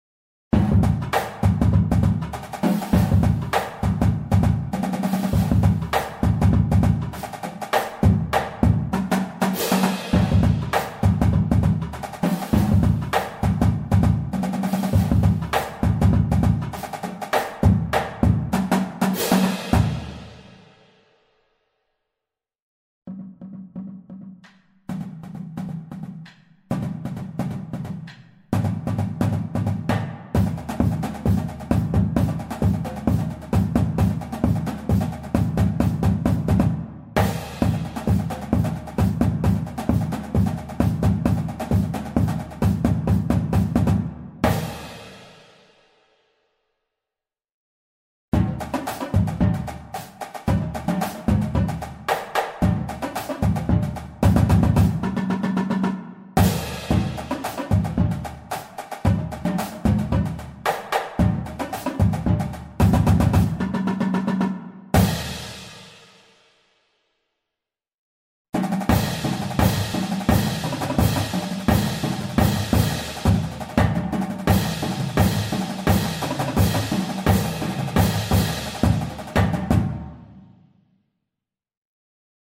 Voicing: Marching Percusion